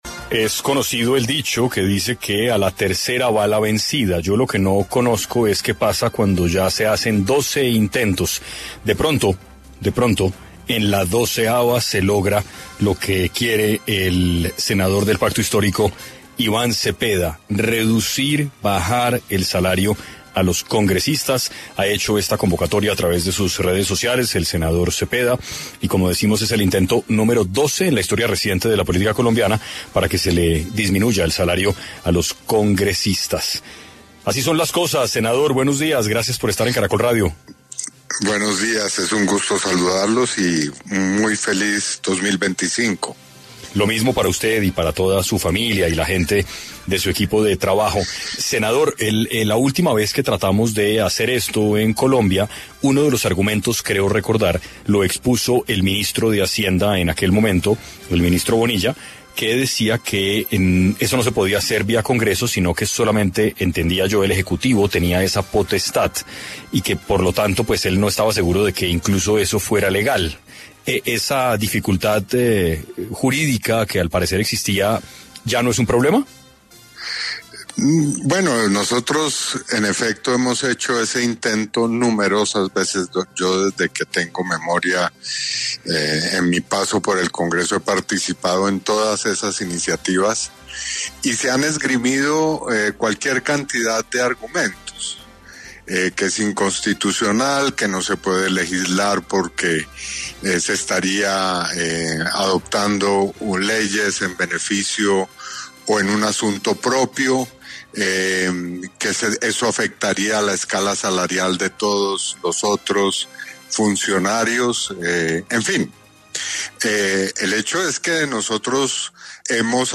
Iván Cepeda, senador de Colombia, estuvo en 6AM de Caracol Radio y habló sobre el proyecto para disminuir el salario de los congresistas.